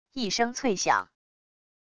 一声脆响――wav音频